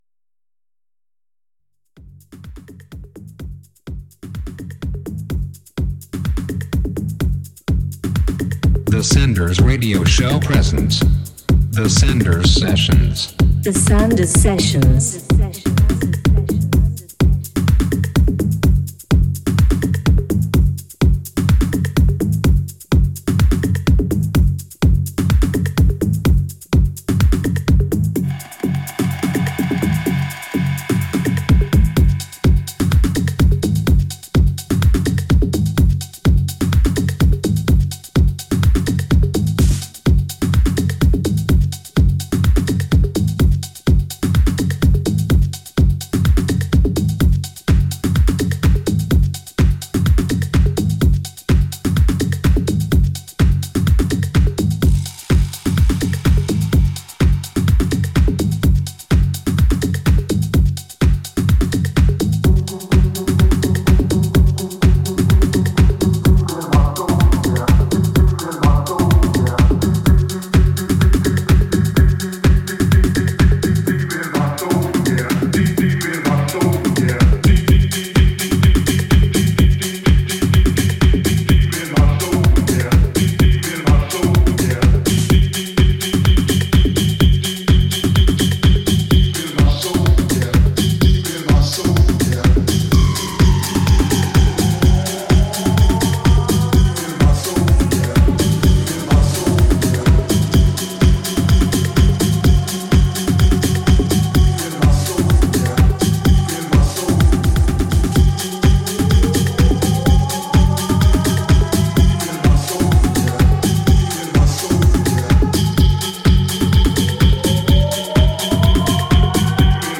one hour of good sounds mixed